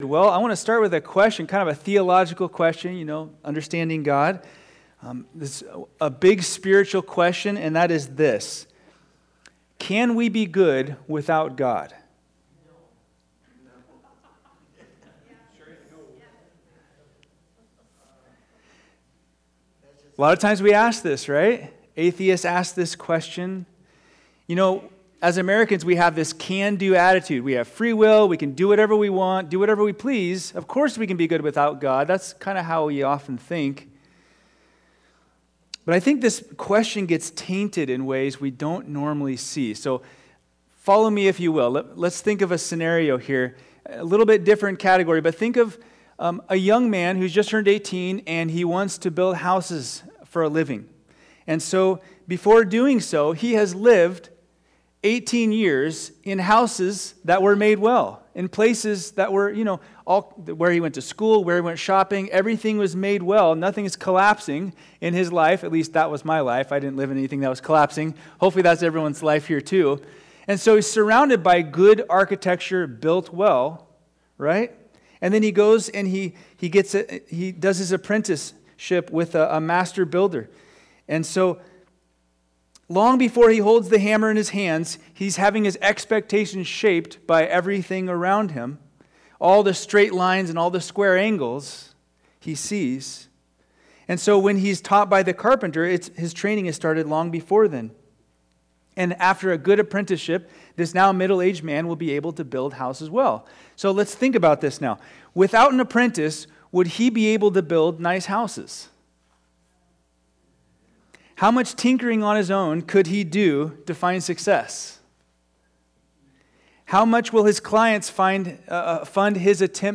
All Sermons Enlivened by Grace April 21